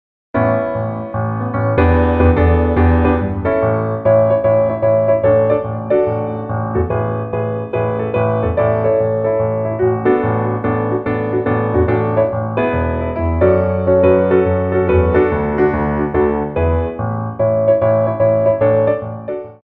Tendus / Grand Battements
4/4 (8x8)